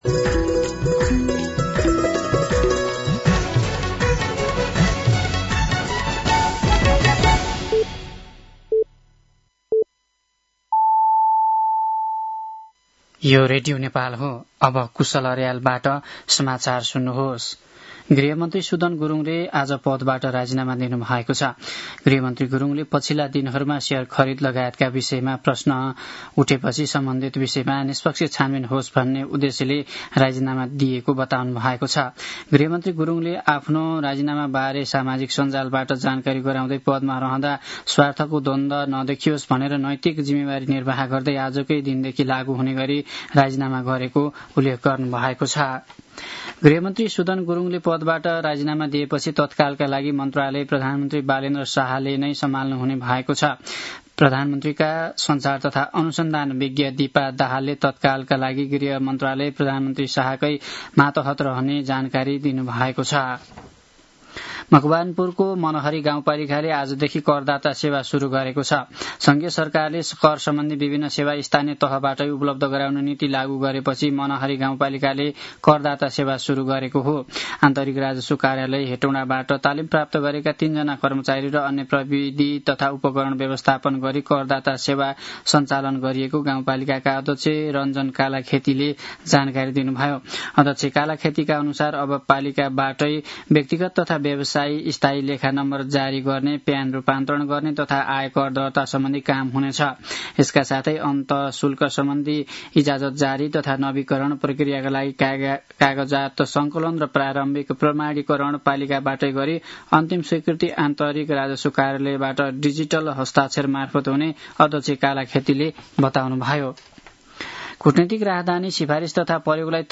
An online outlet of Nepal's national radio broadcaster
साँझ ५ बजेको नेपाली समाचार : ९ वैशाख , २०८३